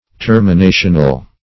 Search Result for " terminational" : The Collaborative International Dictionary of English v.0.48: Terminational \Ter`mi*na"tion*al\, a. Of or pertaining to termination; forming a termination.